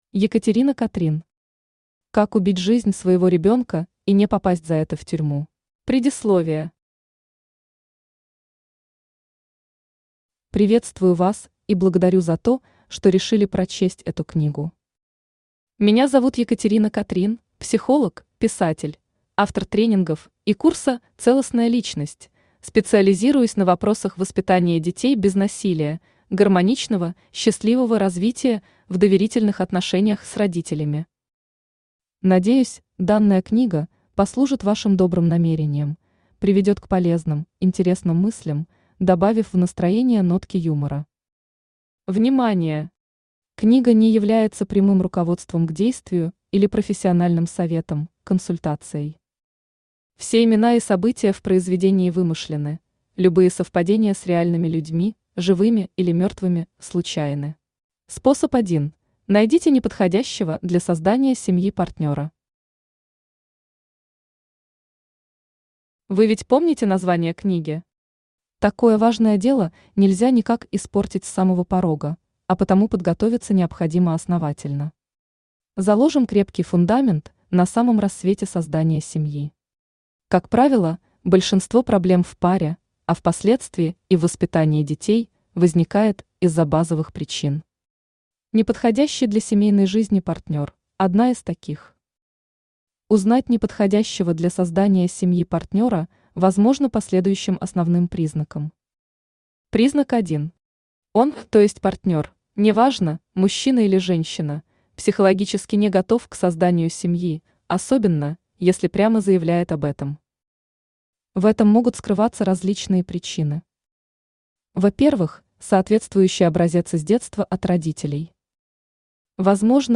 Аудиокнига Как убить жизнь своего ребенка и не попасть за это в тюрьму | Библиотека аудиокниг
Aудиокнига Как убить жизнь своего ребенка и не попасть за это в тюрьму Автор Екатерина Катрин Читает аудиокнигу Авточтец ЛитРес.